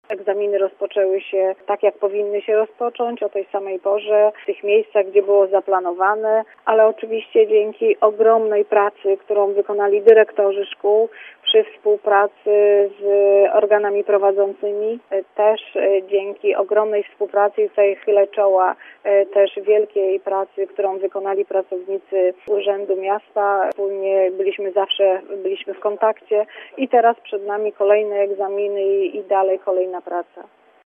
Dziś o godz. 9 rozpoczęły się egzaminy gimnazjalne. Jak mówi lubuski kurator oświaty Ewa Rawa we wszystkich szkołach na terenie Gorzowa egzaminy rozpoczęły się i trwają bez zakłóceń.